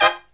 HIGH HIT09-R.wav